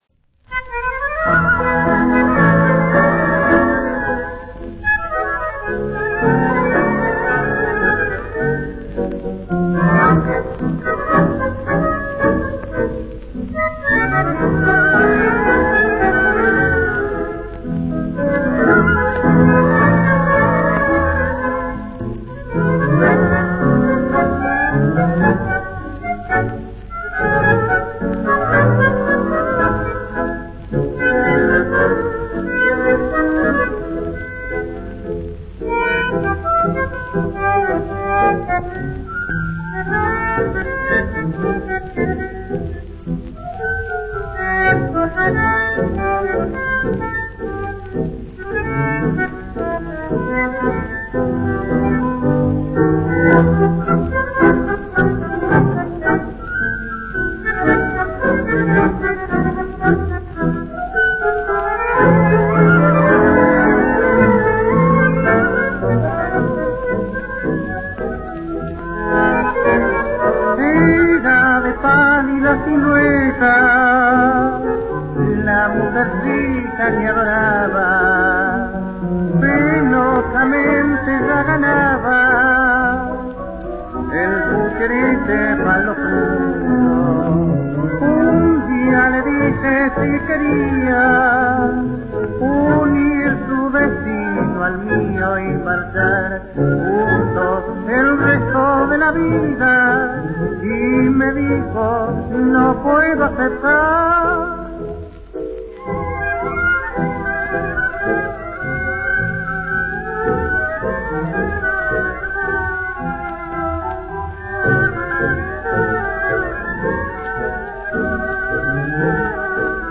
Галерея Танго дня